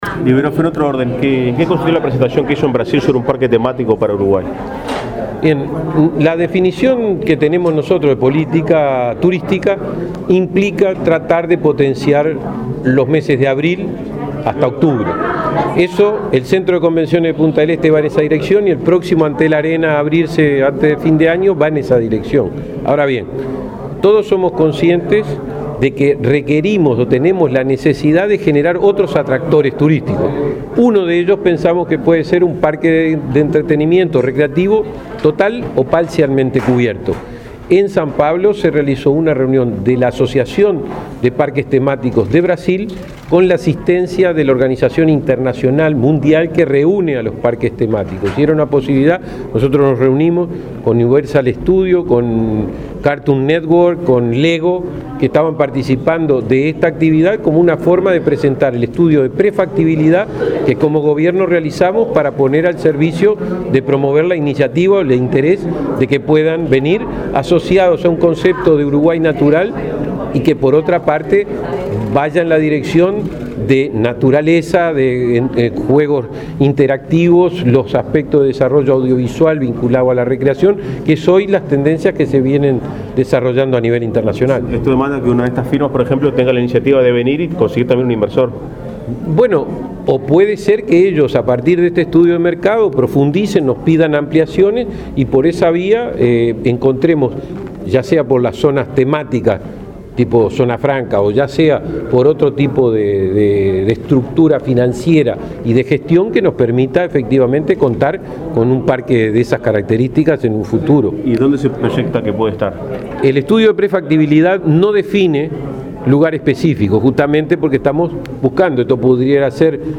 “La definición de política turística implica potenciar los meses de abril a octubre; el Centro de Convenciones de Punta del Este y el Antel Arena va en esa dirección”, sostuvo el subsecretario de Turismo, Benjamín Liberoff, hablando con la prensa el viernes 10, sobre la posibilidad de generar otros atractivos como un parque temático recreativo, tras su participación en San Pablo, Brasil, de la asociación de parques temáticos.